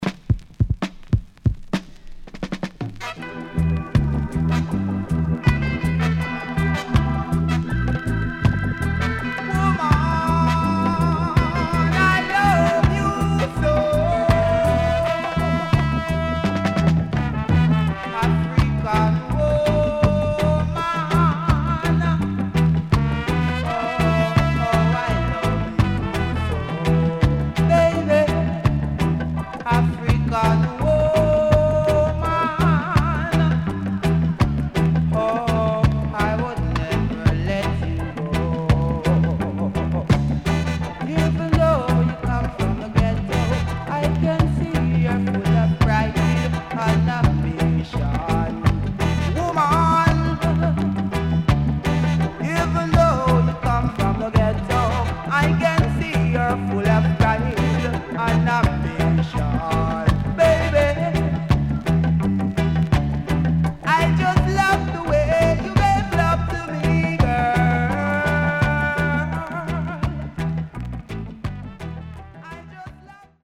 Great Roots